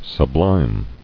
[sub·lime]